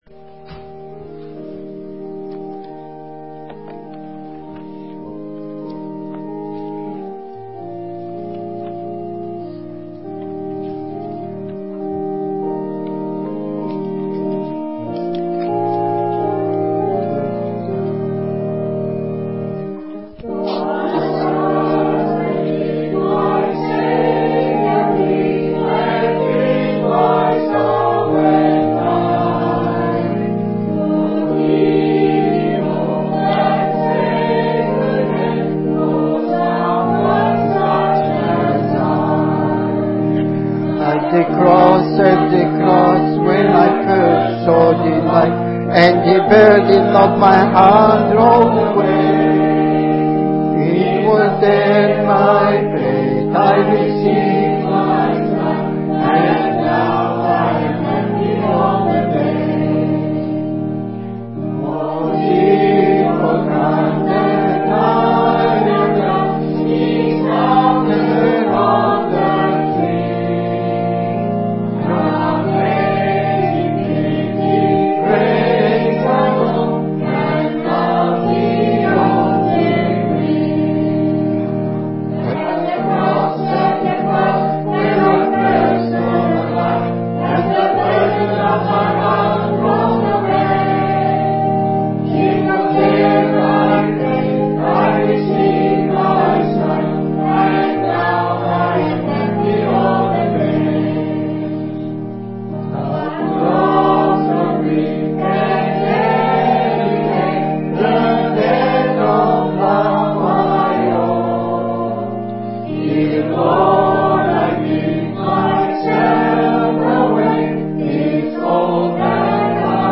Special performances
btn3 SP-1101-1 At The Cross Hymn 163 - Hamilton Congregation 29th January 2011